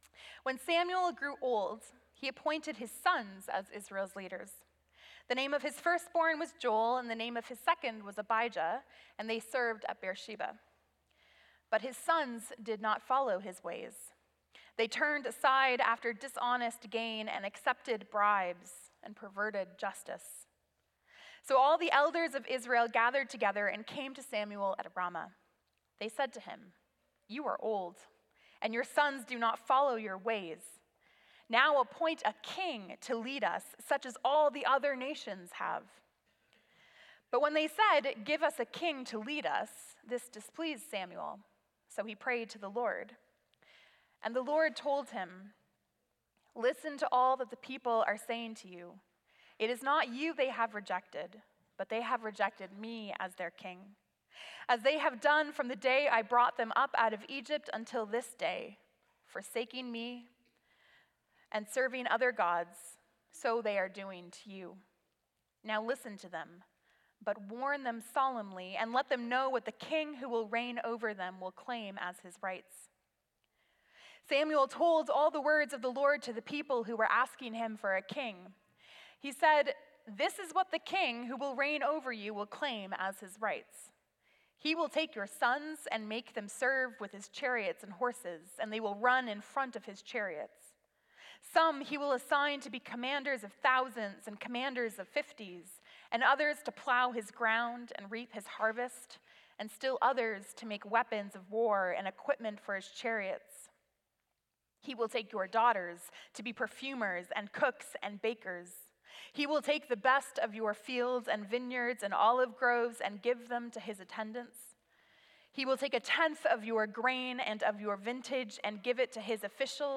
Sermons | Community Christian Reformed Church